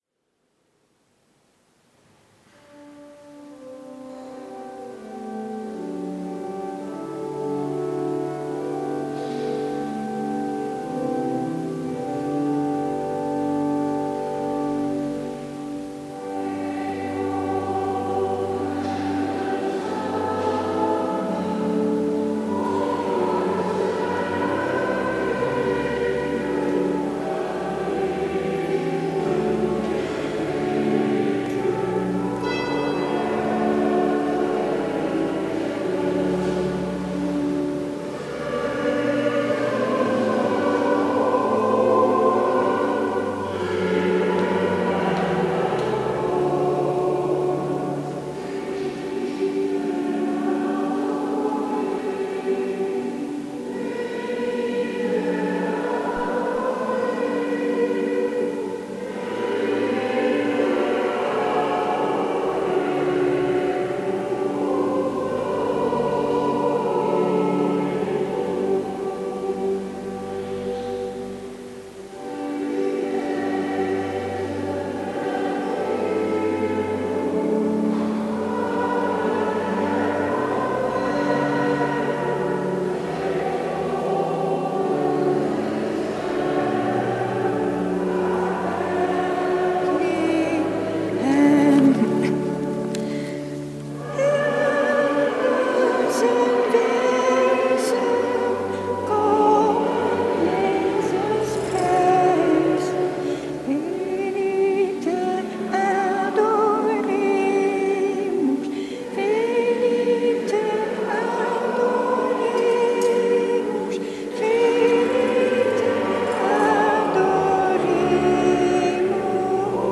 Eucharistieviering beluisteren vanuit de Willibrorduskerk te Wassenaar (MP3)